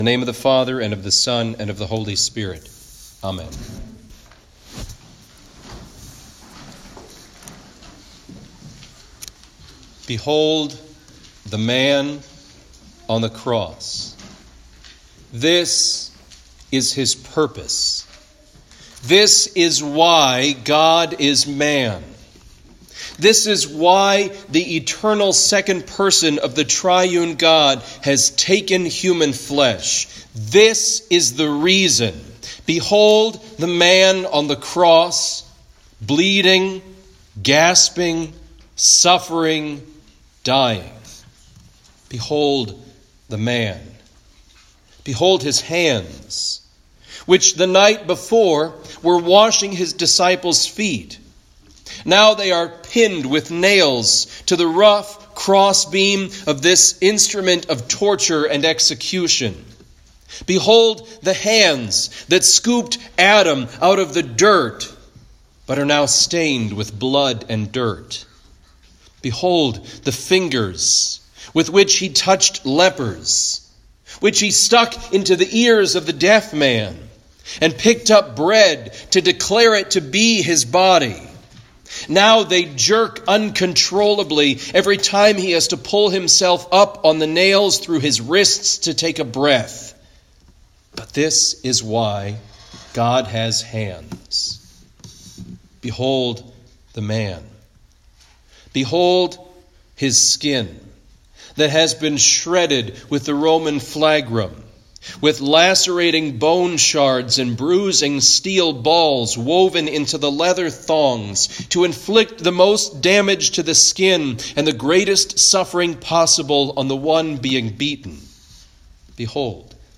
Good Friday Chief Service
Home › Sermons › Good Friday Chief Service